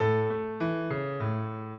piano
minuet7-7.wav